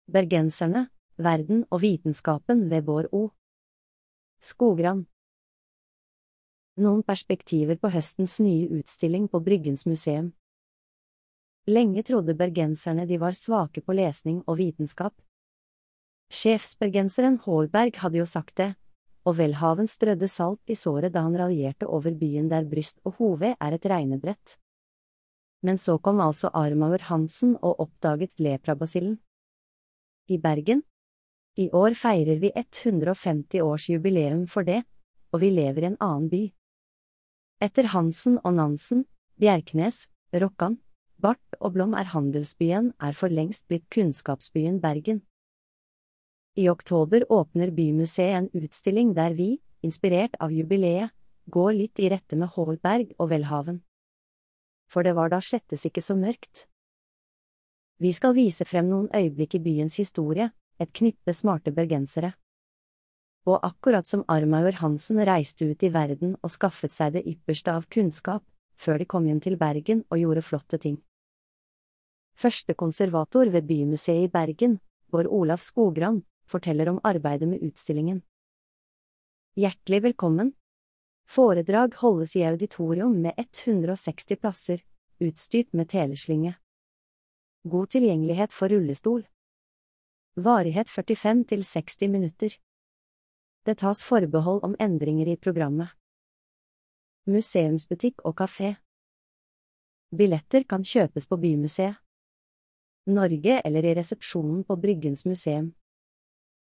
Populærvitenskapelige foredrag. Bryggens Museum.